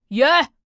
c01_5胖小孩倒数_1.ogg